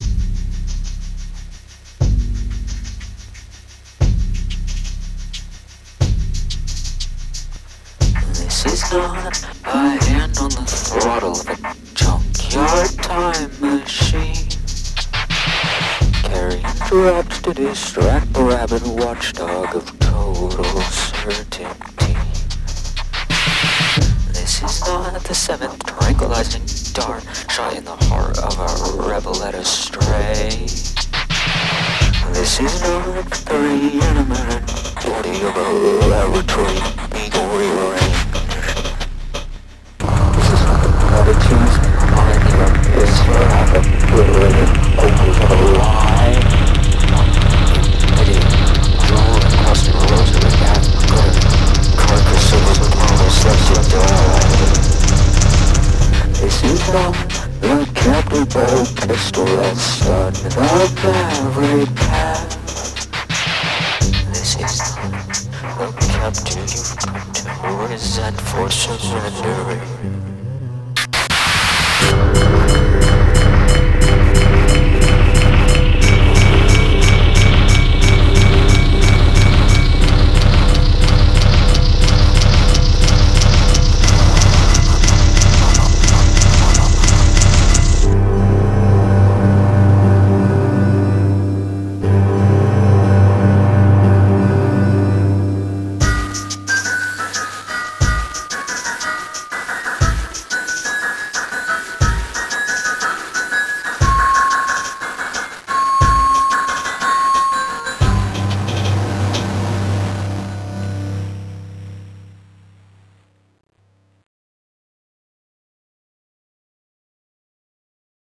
I also made a demo of a song I've been struggling to record for a couple years. Normally I wait until I get a housesitting gig or something to record vocals where no one can hear me, but I just sang really quietly while my roommates were home.